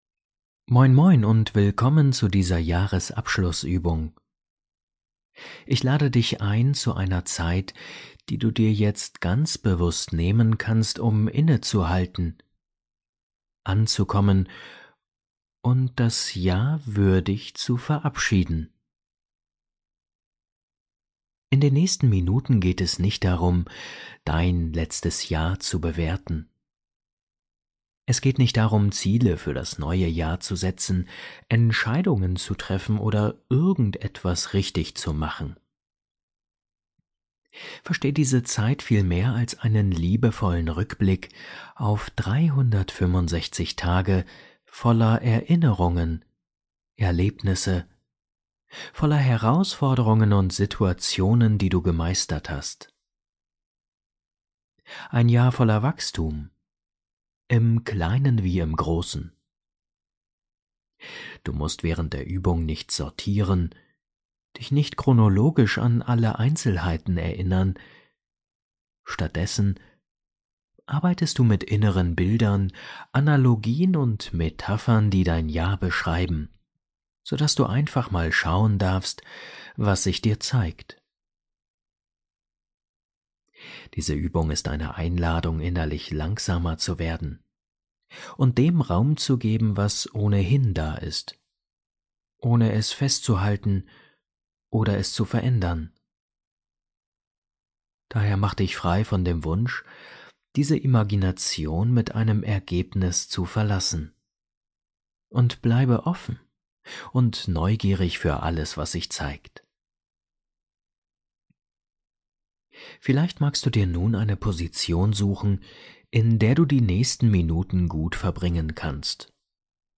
Diese geführte Jahresabschluss-Meditation lädt dich ein, das vergangene Jahr in Ruhe zu würdigen und innerlich abzuschließen. In sanften Bildern begegnest du deinem Jahr als innere Landschaft und öffnest dich achtsam für das, was dich im neuen Jahr begleiten darf.